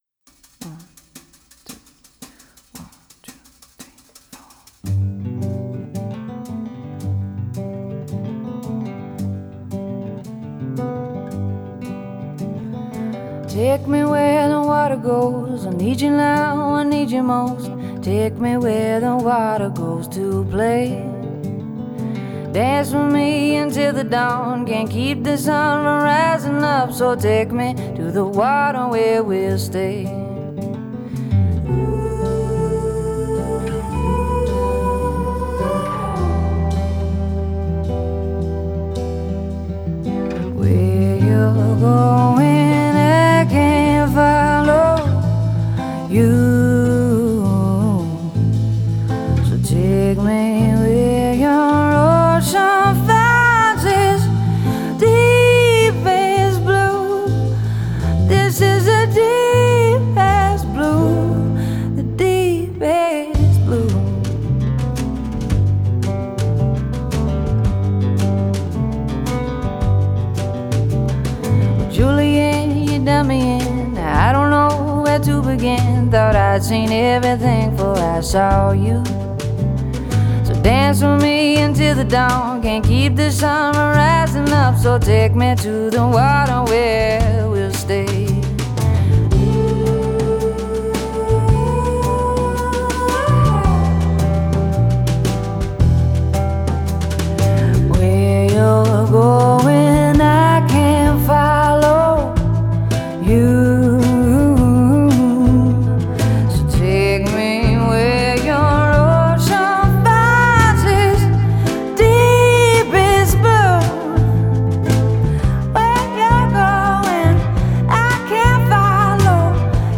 Genre: Indie Pop, Pop Rock,
Singer-Songwriter